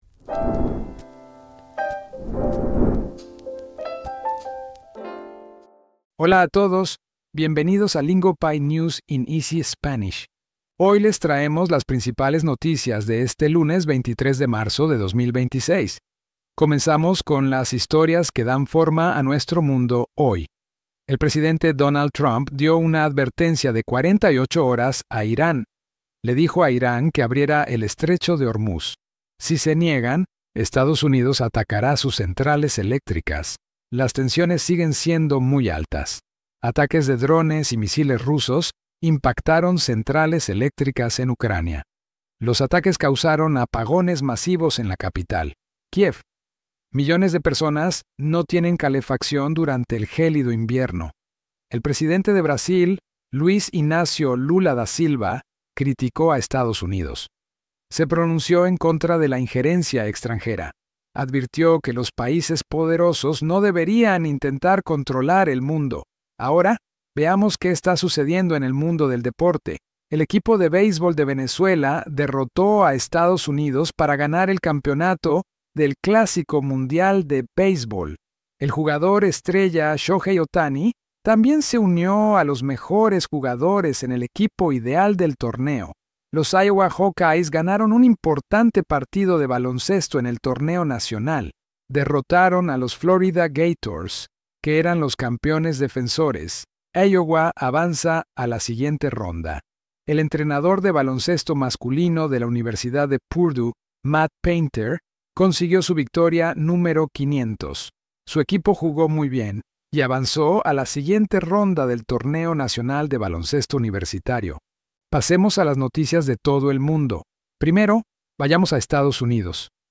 We slow the biggest stories down into clear, beginner-friendly Spanish so your listening practice feels smooth, not stressful.